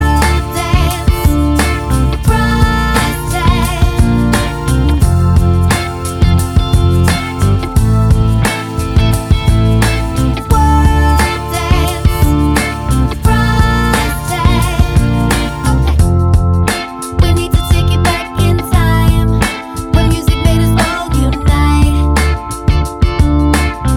For Solo Female Pop (2010s) 3:40 Buy £1.50